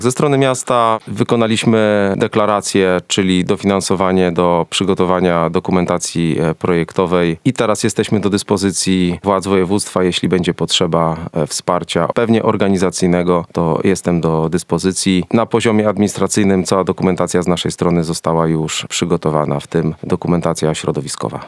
Do tej pory wszystkie prace przebiegają zgodnie z planem – mówi burmistrz Żuromina Michał Bodenszac.
Miasto jest już gotowe na podjęcie kolejnych działań – mówi burmistrz.